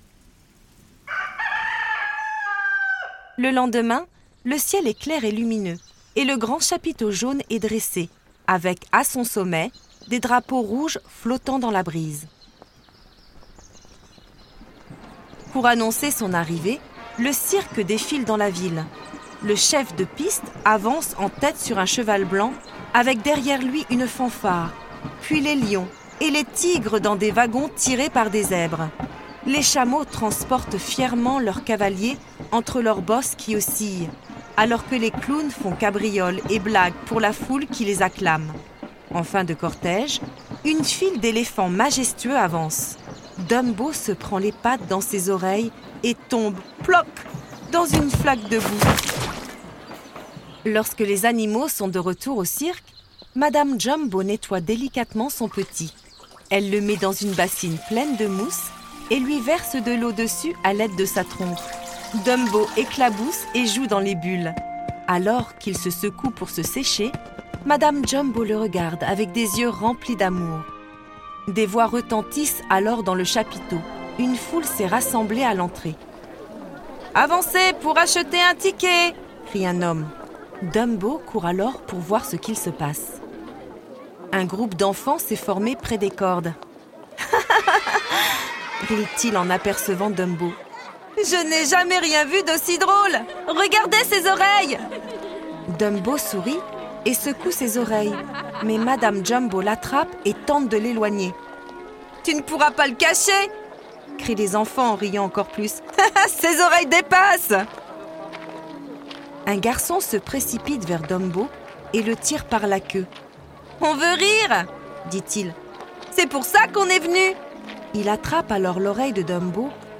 03 - Chapitre 03_ Dumbo - L'histoire à écouter_ Dumbo.flac